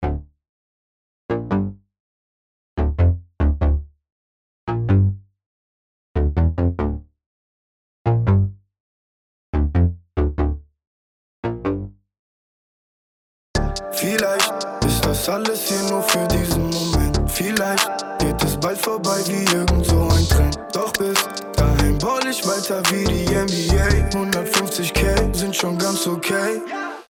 Die Strecke 1 ist nur der Bass gefolgt von der gleichen Strecke Titel.